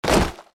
impact_bodyfall.mp3